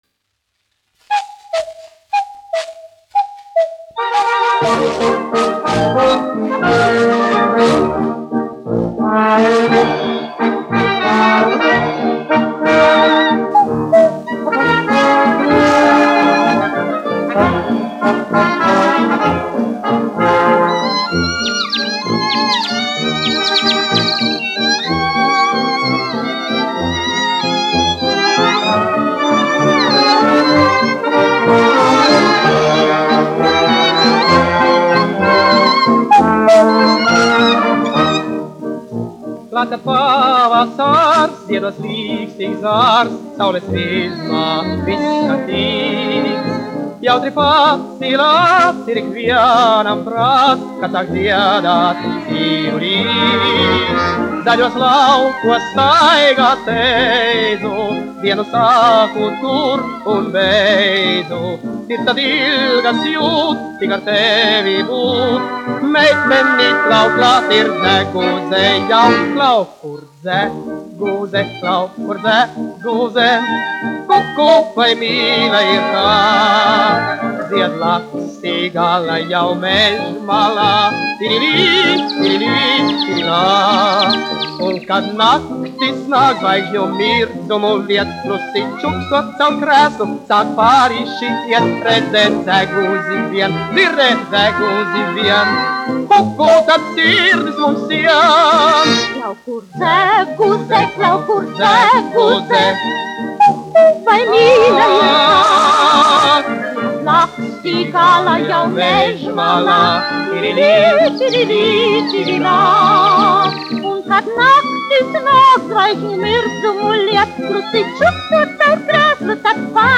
1 skpl. : analogs, 78 apgr/min, mono ; 25 cm
Populārā mūzika
Skaņuplate
Latvijas vēsturiskie šellaka skaņuplašu ieraksti (Kolekcija)